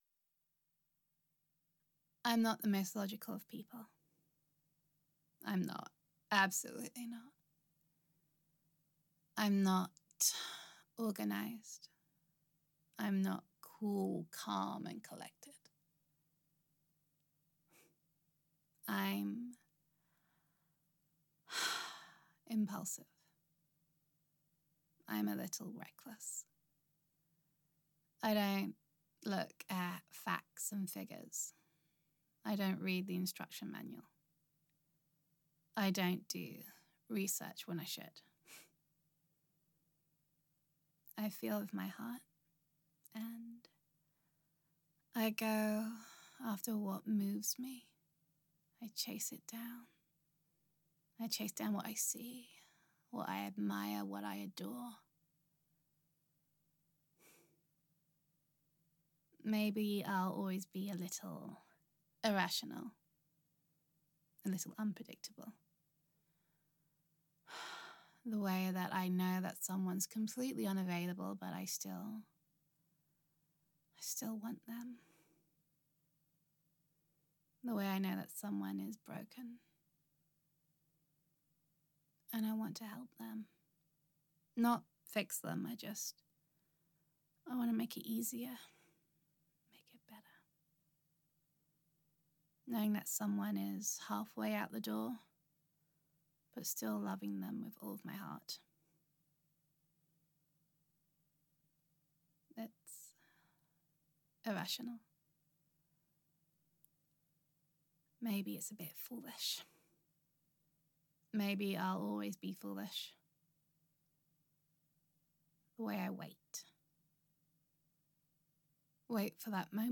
[F4A]